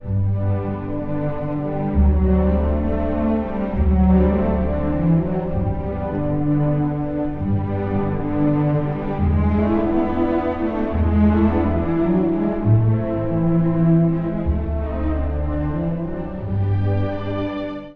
（第二主題）
クラリネットとオーボエによる民謡風の第一主題や、チェロの第二主題が登場しますが、それらが調性の不安定さの中で揺れ動きます。
まるで、静かな葛藤と抑えきれない情熱が交錯するような音楽。